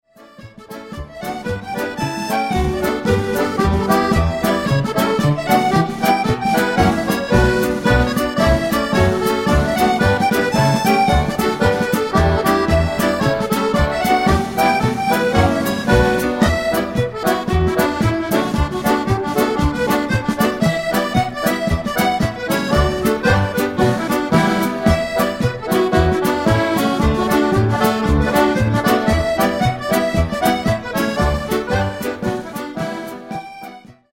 8 x 32 Strathspey